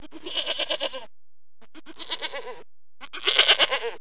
جلوه های صوتی
دانلود صدای حیوانات جنگلی 115 از ساعد نیوز با لینک مستقیم و کیفیت بالا